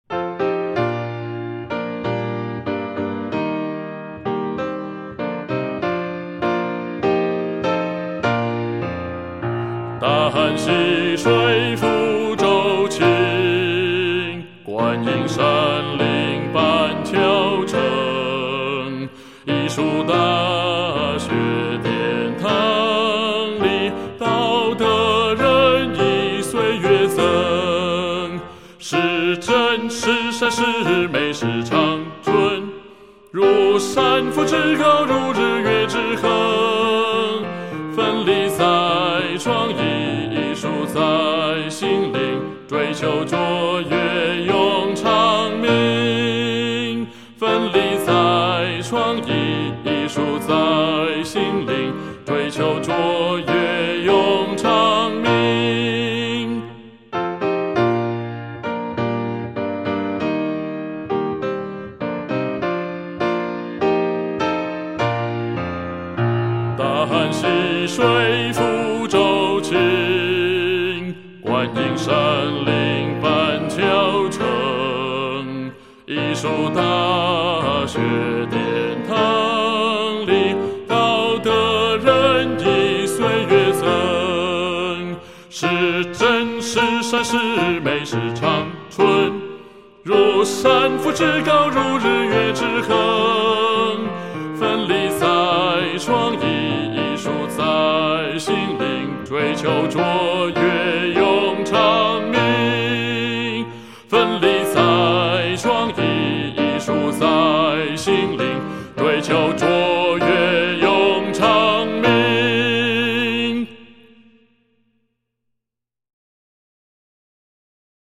調 中庸速度